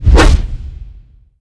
troll_warrior_swish.wav